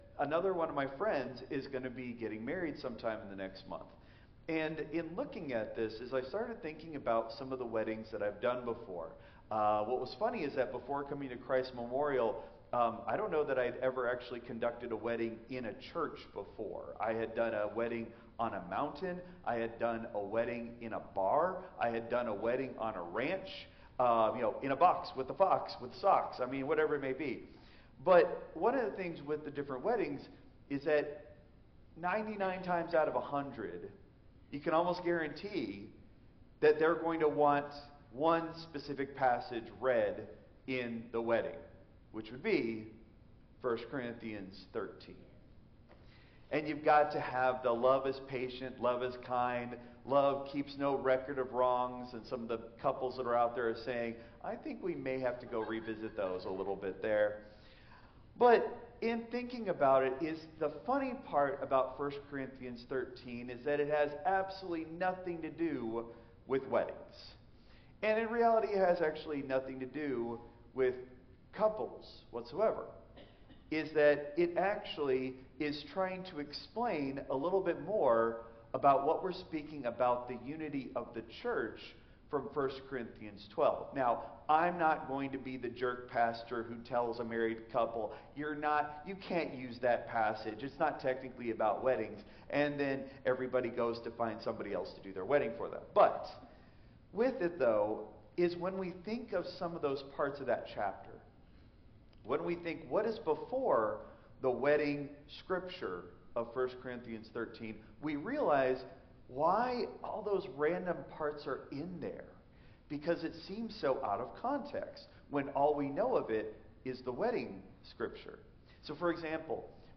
Christ Memorial Lutheran Church - Houston TX - CMLC 2025-01-19 Sermon (Traditional)